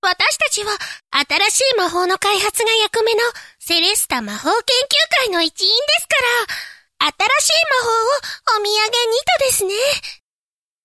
グラフィック名前備考サンプルボイス
性別：女